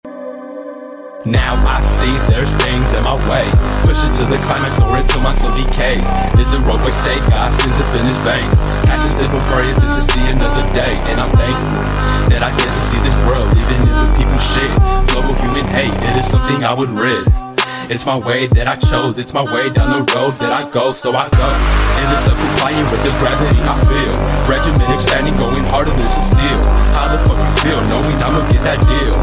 • Качество: 192, Stereo
атмосферные
Trap
Rap
Bass